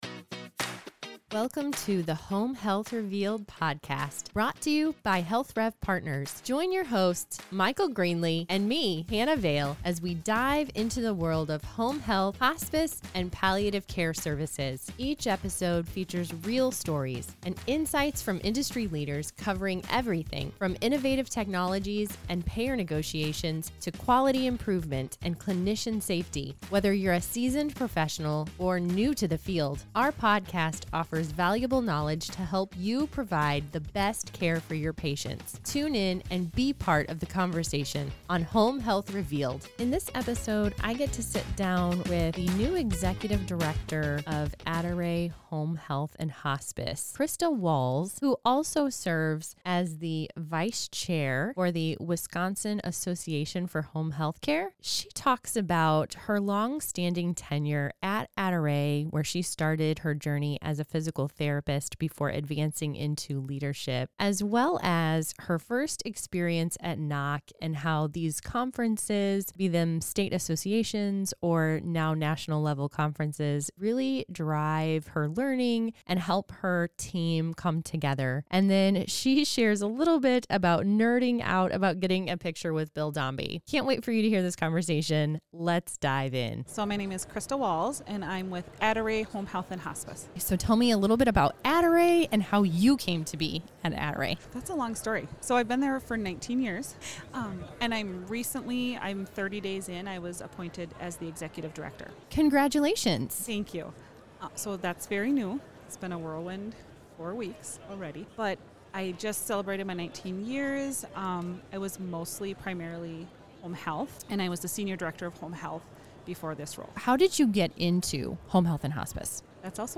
This is a can't miss episode for anyone passionate about home health leadership and anyone who loves a Wisconsin accent!